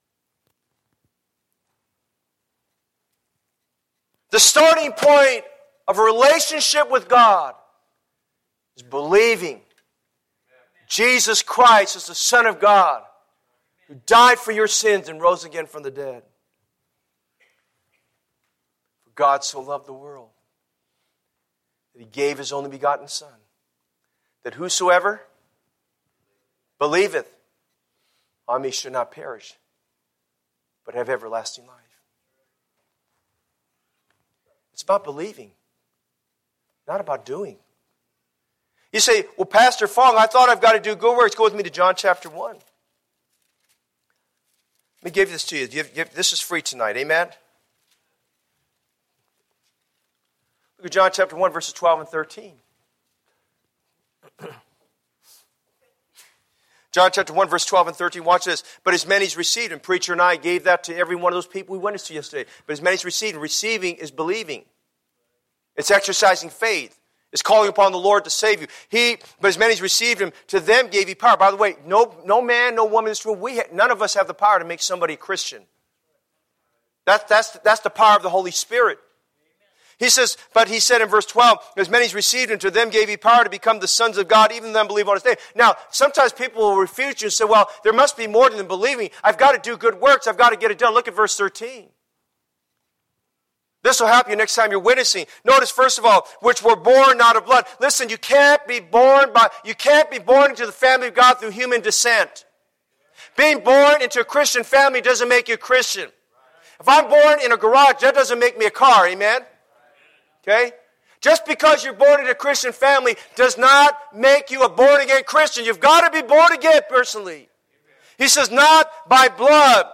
A message from the series "Revival."